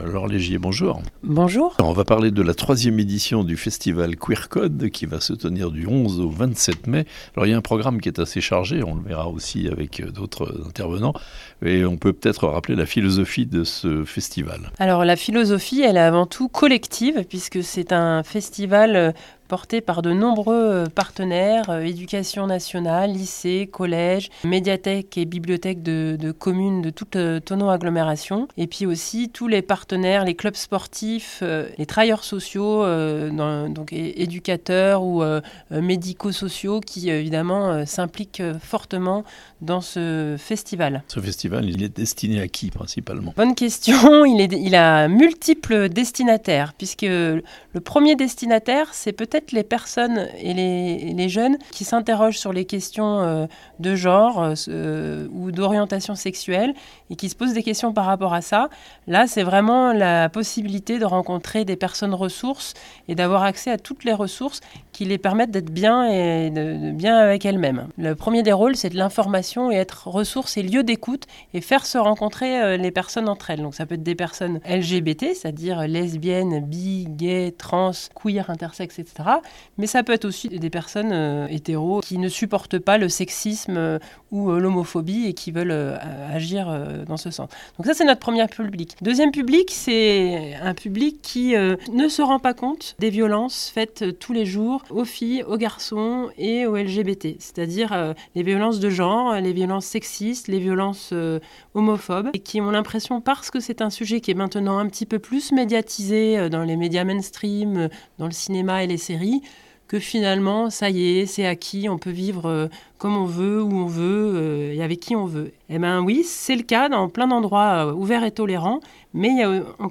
3ème édition du festival "Queer Code" dans toute l'agglomération de Thonon (interviews)